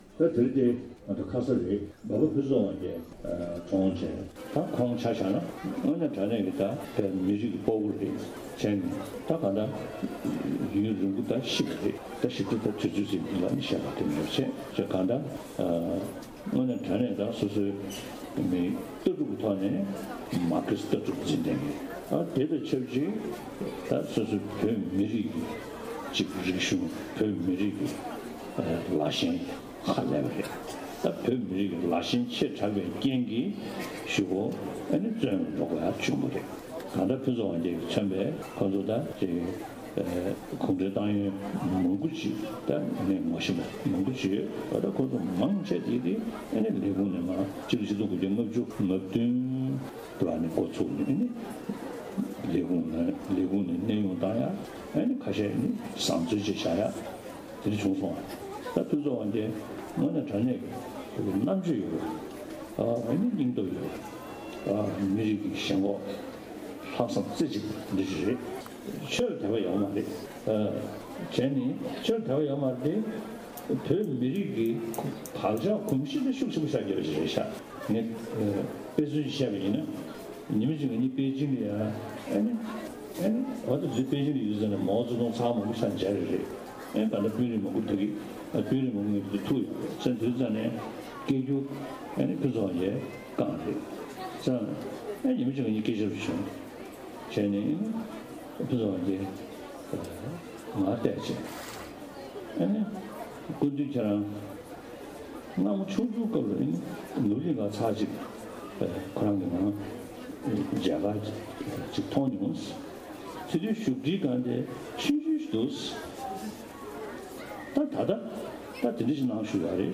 ༸གོང་ས་མཆོག་ནས་འབའ་པ་ཕུན་ཚོགས་དབང་རྒྱལ་ལ་བསྔགས་བརྗོད་གནང་བ། སྒྲ་ལྡན་གསར་འགྱུར།